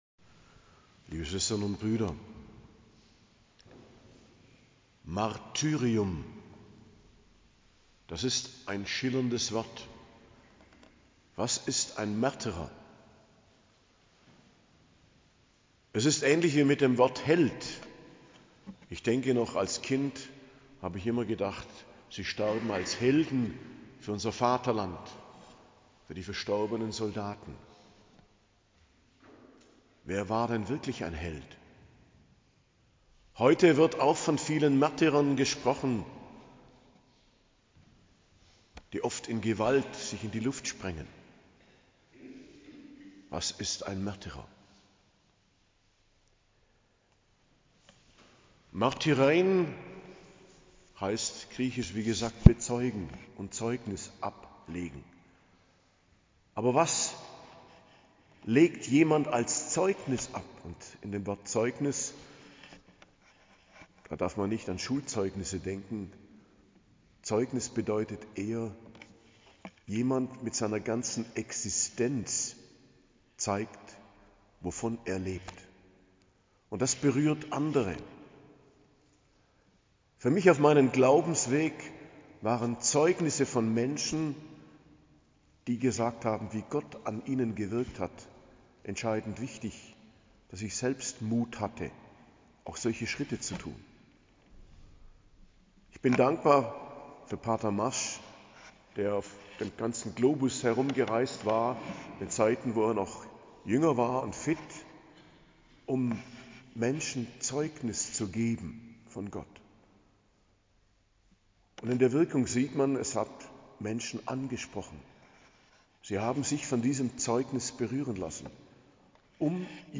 Predigt am Fest des Hl Stephanus, erster Märtyrer der Kirche, 26.12.2024